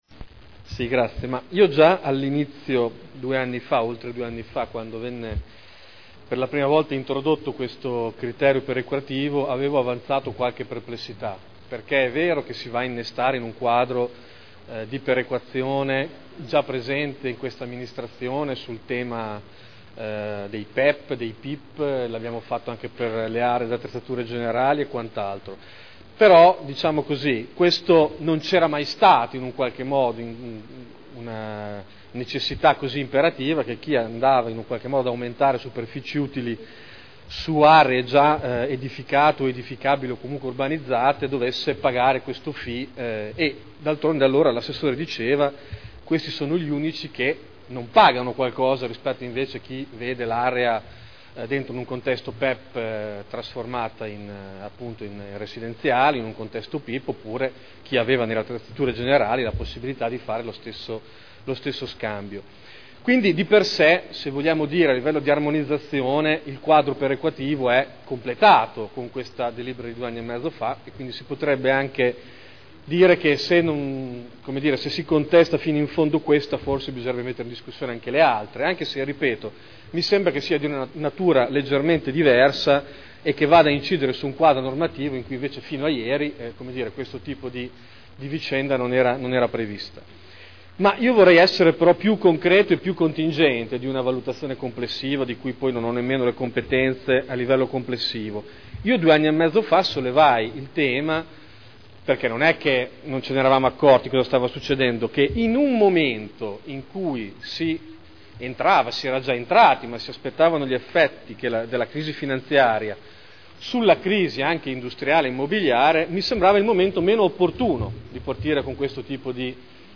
Seduta del 6/12/2010. Dichiarazione di voto su delibera: Art. 14.1 del testo coordinato delle norme di PSC (Piano Strutturale Comunale) POC (Piano Operativo Comunale) RUE (Regolamento Urbanistico Edilizio) – Modifiche al regolamento attuativo – Approvazione (Commissione consiliare del 30 novembre 2010)